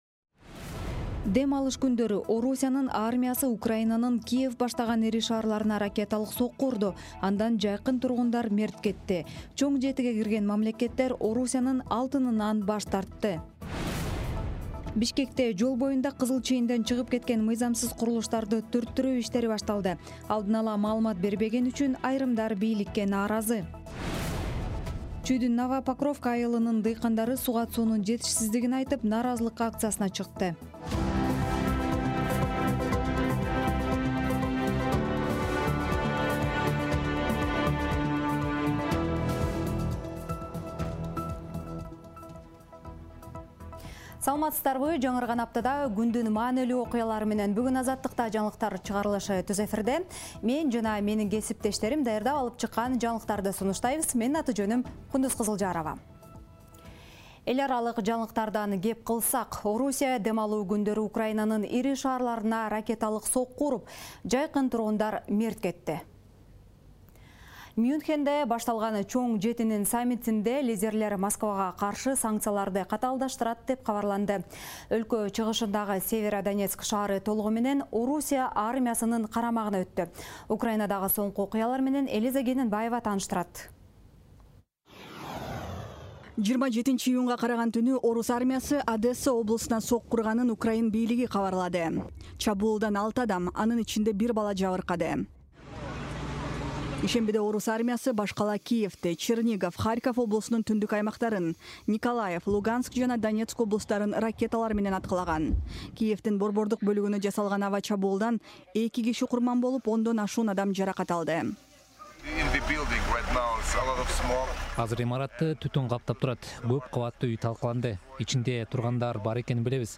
Жаңылыктар | 27.06.2022 | Менчик медициналык мекемелер текшерилет